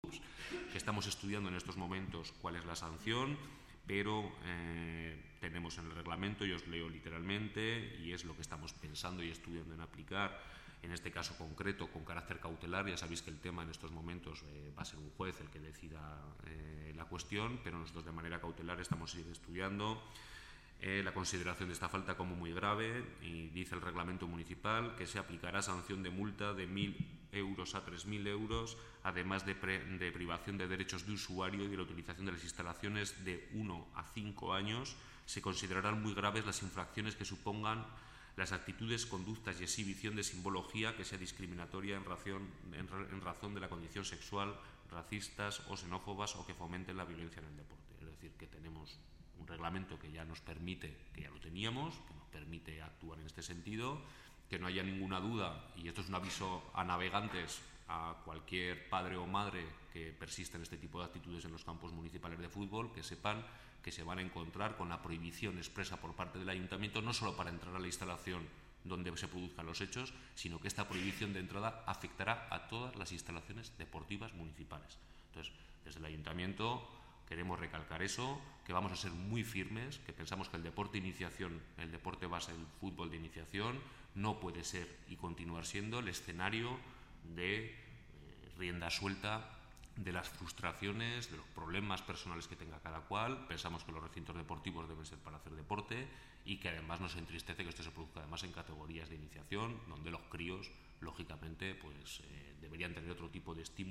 DECLARACIONES DEL CONCEJAL PABLO HÍJAR SOBRE EL EXPEDIENTE SANCIONADOR POR INSULTOS RACISTAS